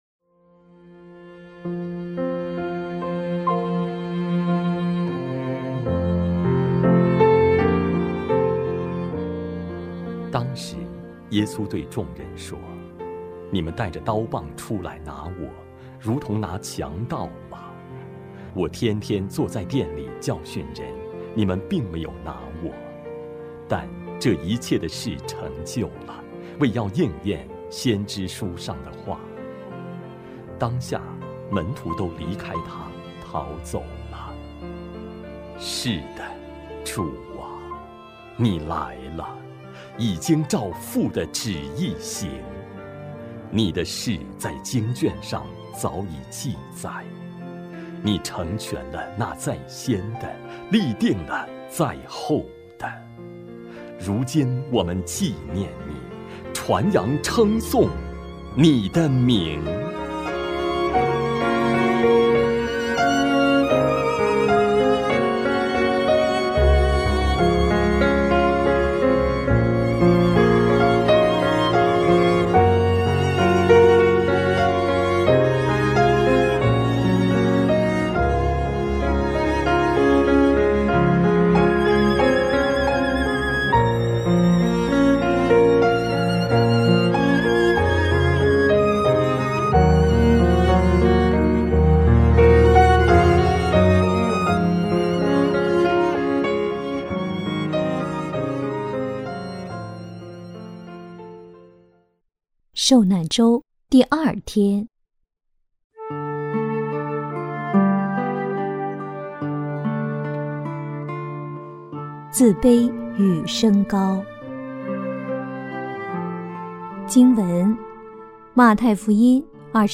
02-第二天：自卑与升高-权柄日-Christe-salvator-泰泽音乐.mp3